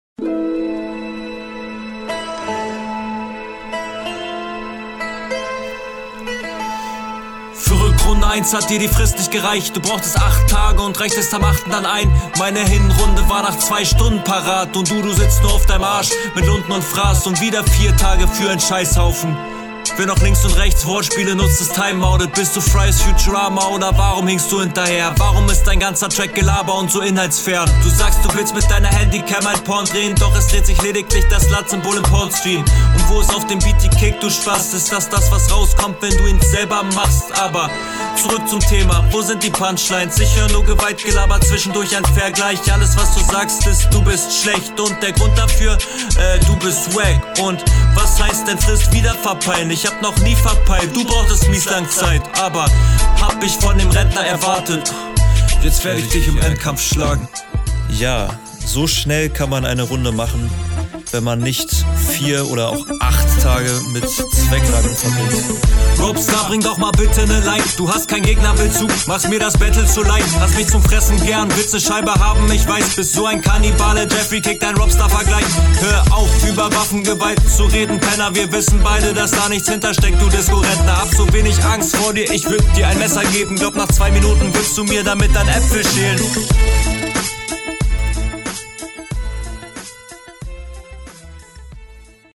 flowlich hier schwacher als dein gegner aber trotzdem safe, alles onbeat. kontervorteil genutzt, die line …